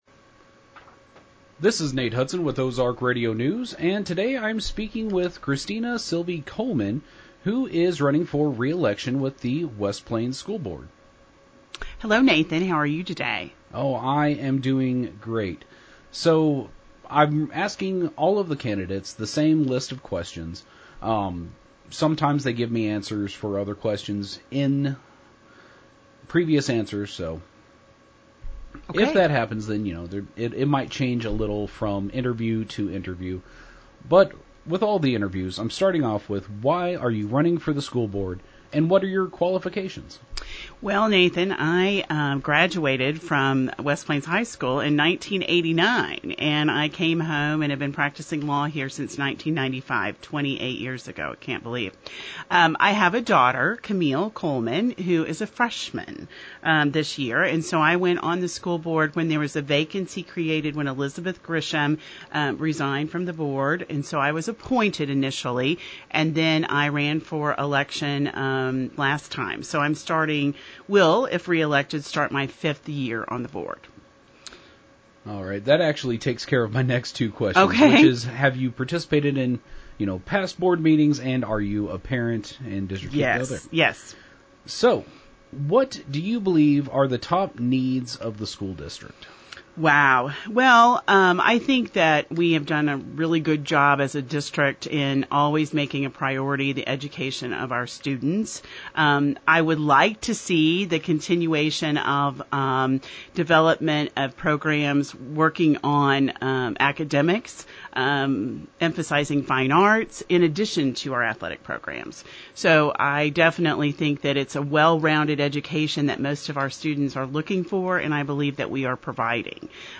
News Team 7 will be releasing our interviews with each of the candidates throughout the week.